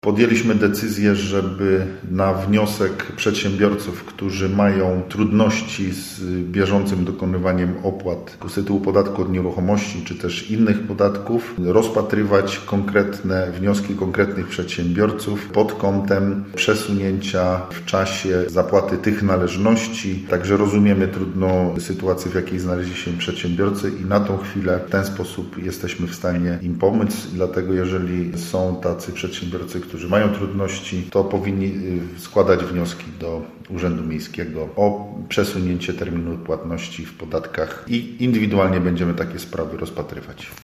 Mówi burmistrz Marcin Marzec: